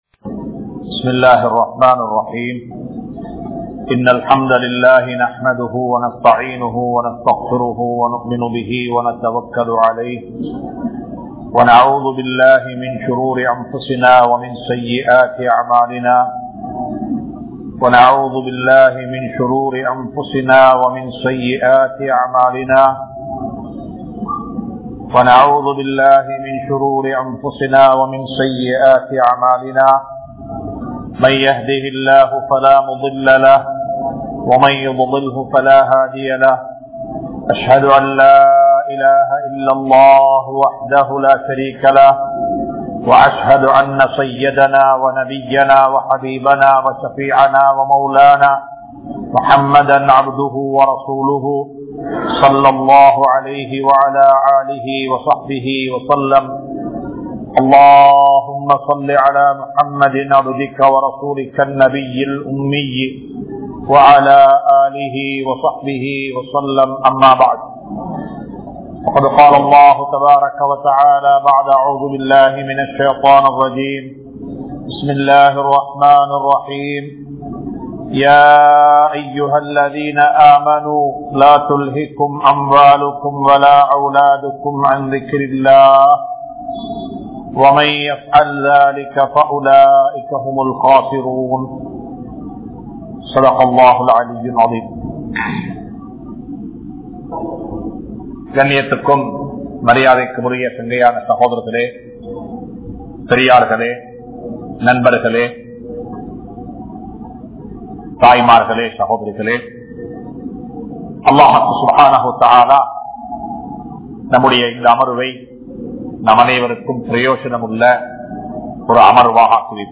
Allah`vai Ninaivu Koorungal (அல்லாஹ்வை நினைவு கூறுங்கள்) | Audio Bayans | All Ceylon Muslim Youth Community | Addalaichenai